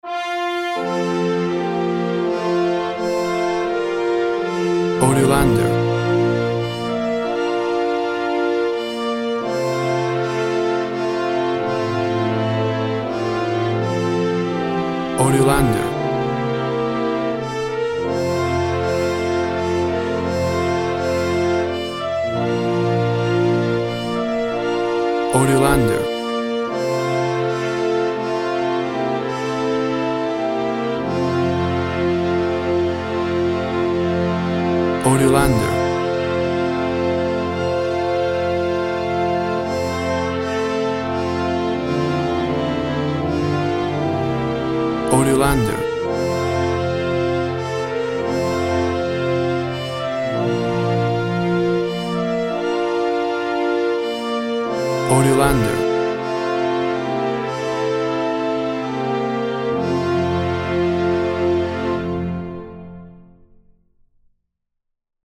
A vibrant and heartwarming traditional version
including strings And brass
Tempo (BPM) 75